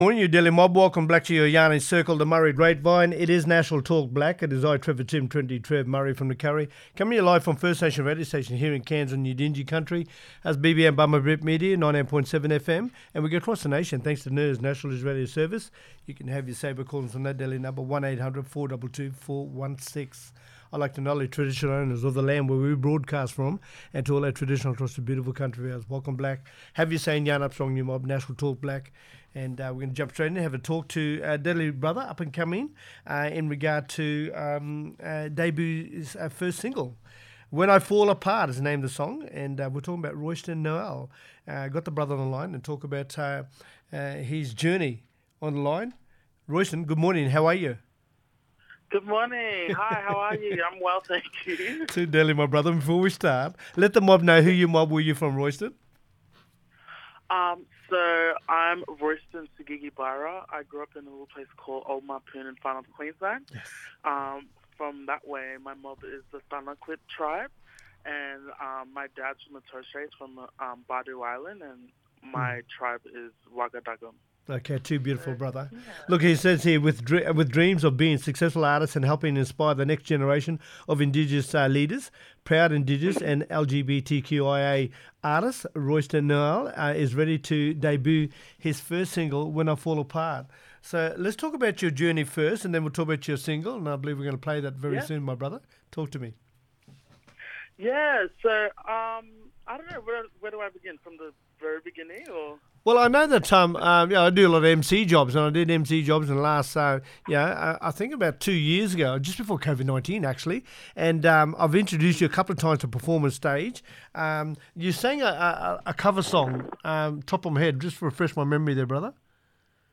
Aurukun Mayor Keri Tamwoy, Aurukun Shire Council talking about the exhibition success for Wik and Kugu Art Centre. Aurukun’s famous ku’ (camp dog) carvings are among 160 works installed in the inaugural display of Aboriginal and Torres Strait Islander art in the newly located Yiribana Gallery at the Art Gallery of New South Wales.